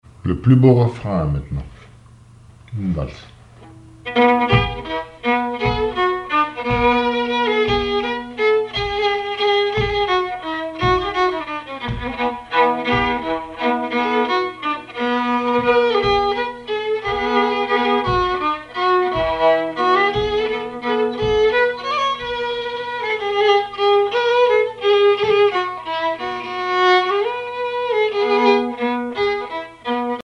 violoneux, violon
valse musette
Pièce musicale inédite